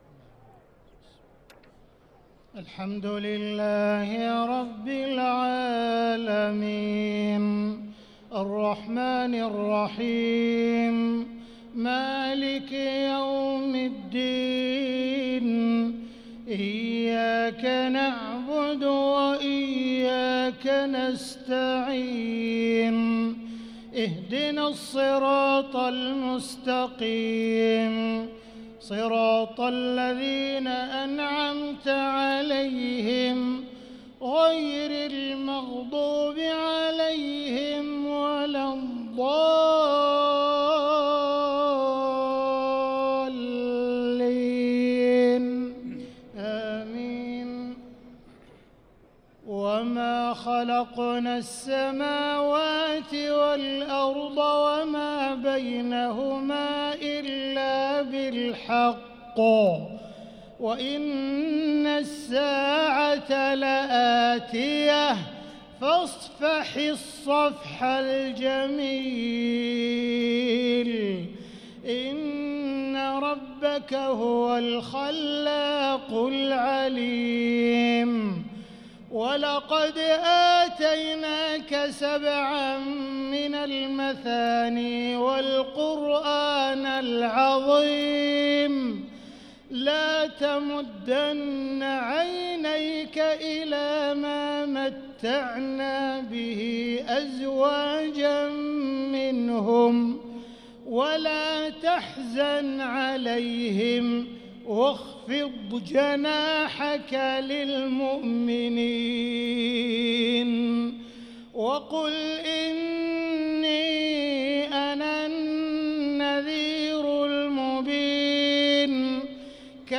صلاة المغرب للقارئ عبدالرحمن السديس 29 رمضان 1445 هـ